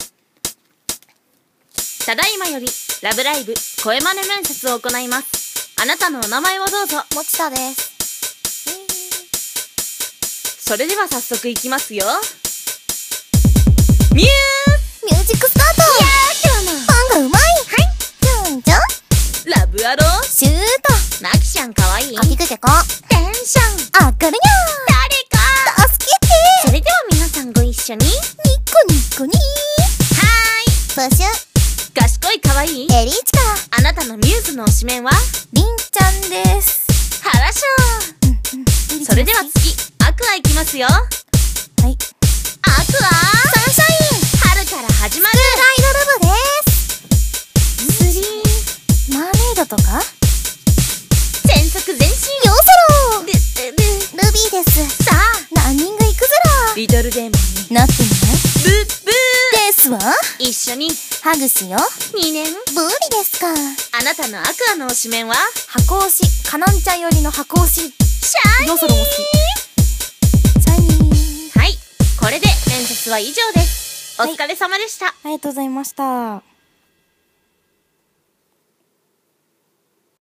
ラブライブ声真似面接！